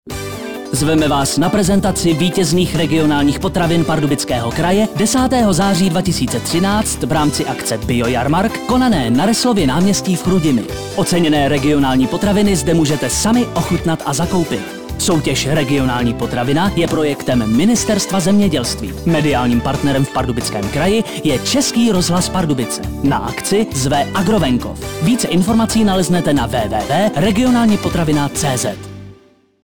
Upoutávky v Českém rozhlase Pardubice na ochutnávky  vítězných regionálních potravin v Pardubickém kraji: